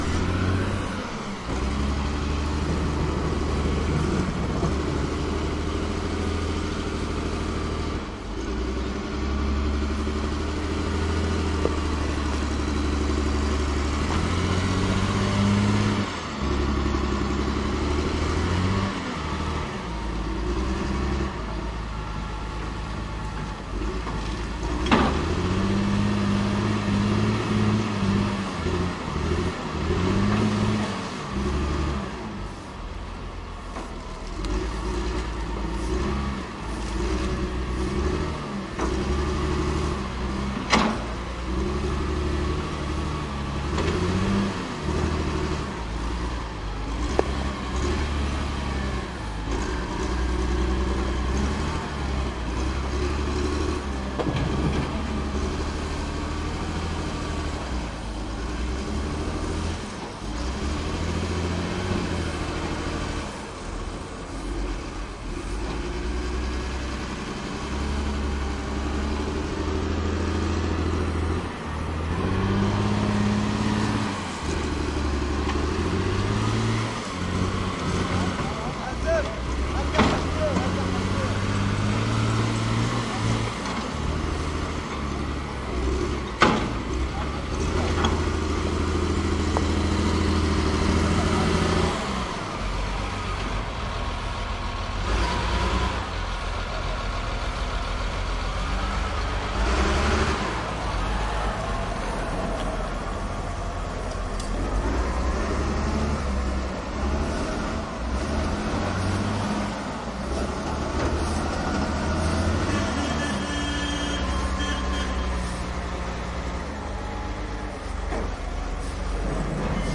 蒙特利尔 " 噪音金属垃圾机小巷垃圾车 加拿大蒙特利尔
描述：噪声金属垃圾机胡同垃圾车蒙特利尔，Canada.flac
标签： 卡车 机器 胡同 金属 垃圾 噪音 垃圾
声道立体声